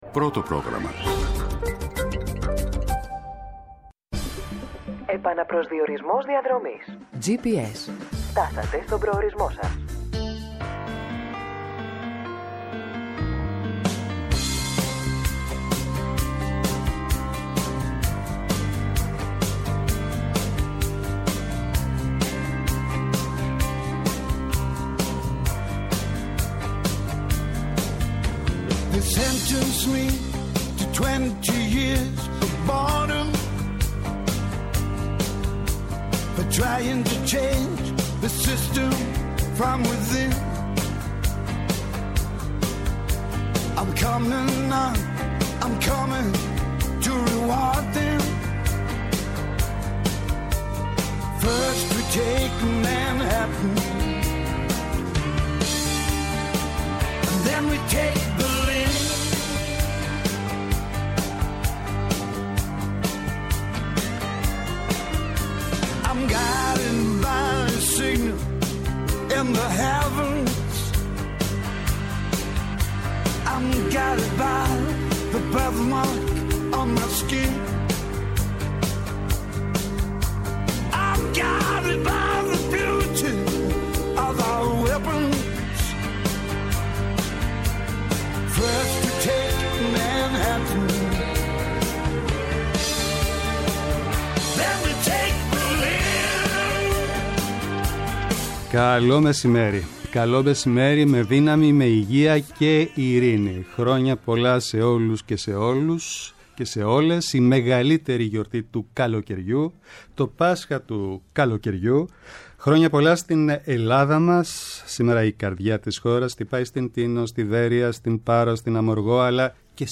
Εκτάκτως σήμερα, ανήμερα Δεκαπενταύγουστο, 12:30-14:00 καλεσμένοι :